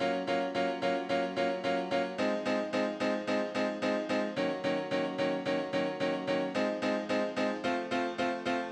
03 Piano PT4.wav